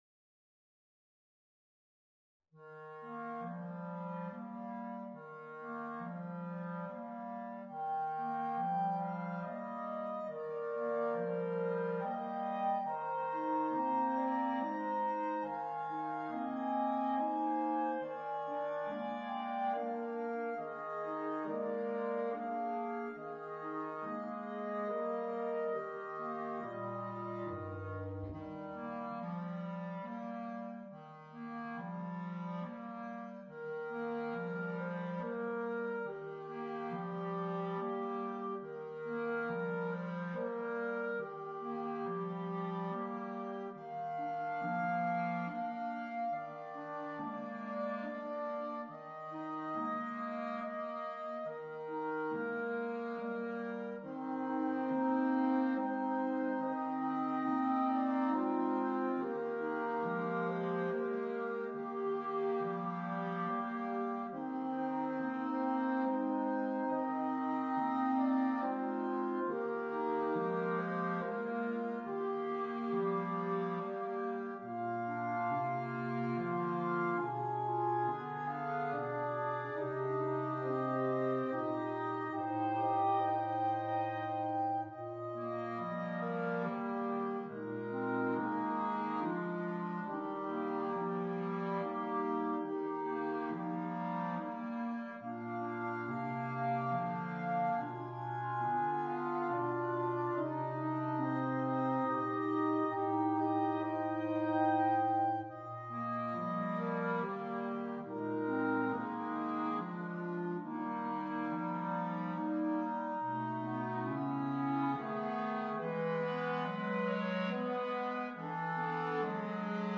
QUINTETTO